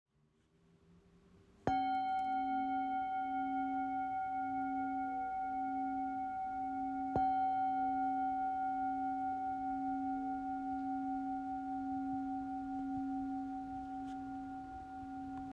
Traditional Tibetan Bowl · unengraved Size L (approx. 25 cm)
Magnificent traditional unengraved Tibetan bowl, supplied with its cushion and mallet.
The handcrafting of these bowls means that each one is unique in terms of its sound and vibration.